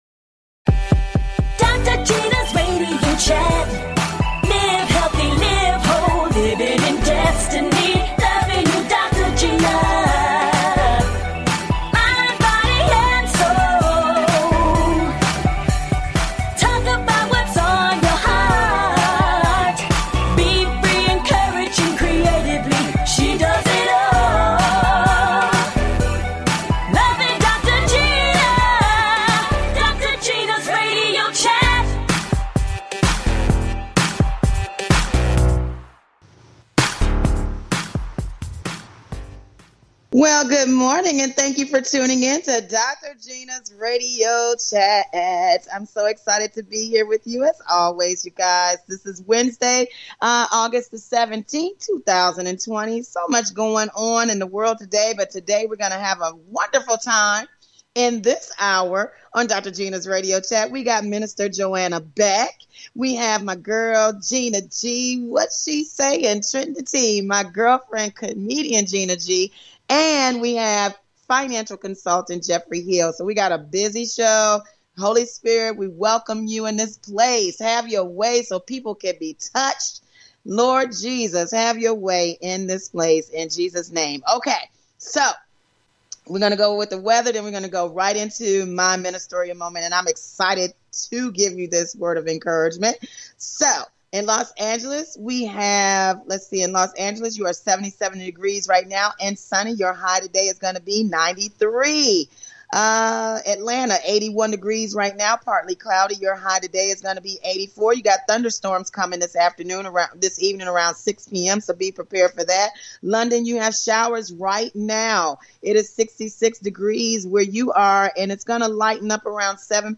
Talk Show
Fun! Exciting! And full of laughter!